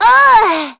Yanne_L-Vox_Damage_04.wav